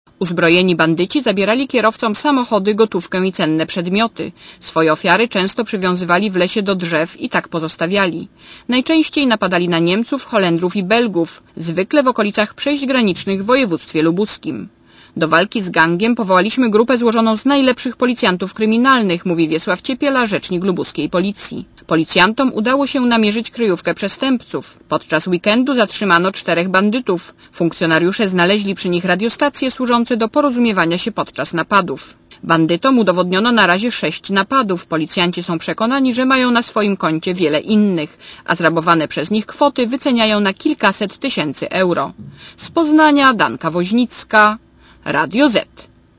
Posłuchaj relacji reporterki Radia Zet (180 KB)